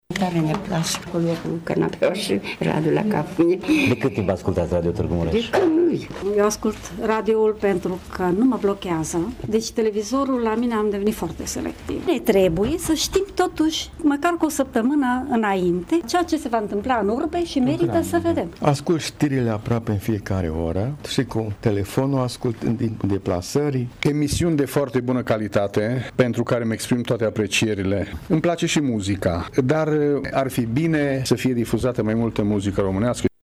Unii dintre oaspeți au spus că ascultă Radio Tîrgu-Mureș din copilărie, și că preferă radioul în detrimentul televizorului.